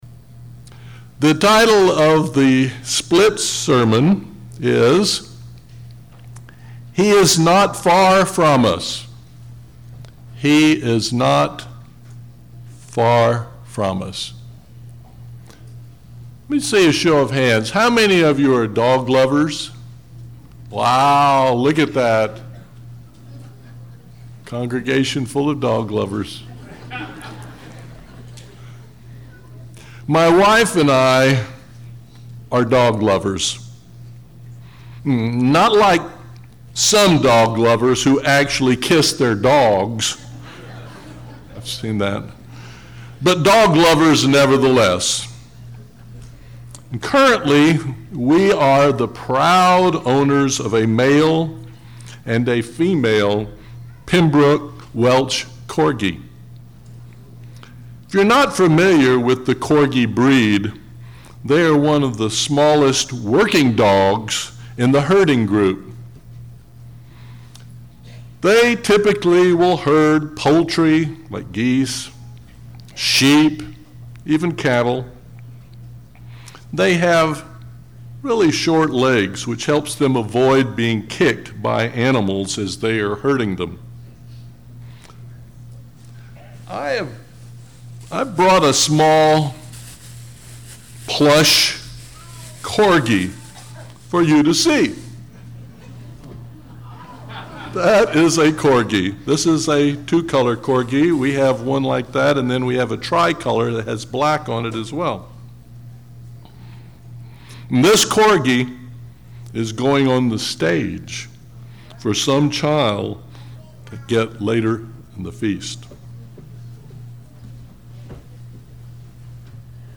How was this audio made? This sermon was given at the Lake Texoma, Texas 2017 Feast site.